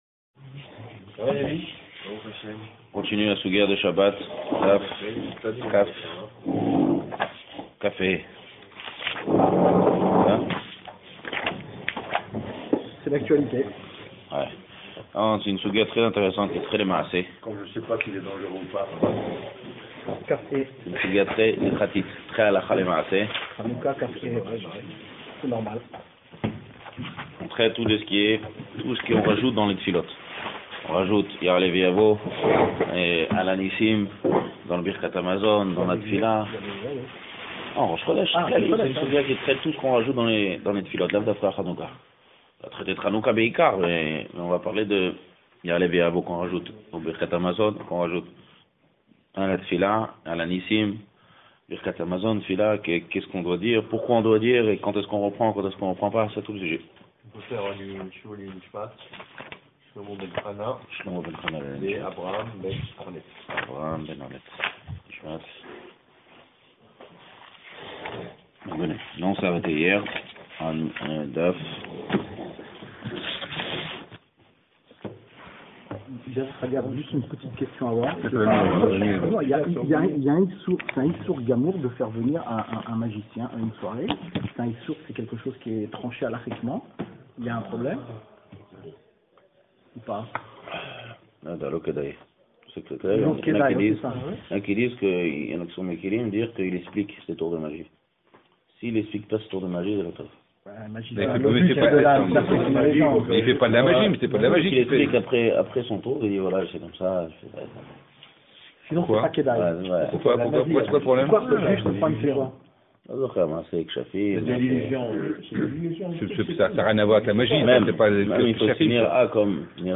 Cours sur Hanoukka (cours de Guémara) donné le mardi 23 décembre 2014 à Raanana.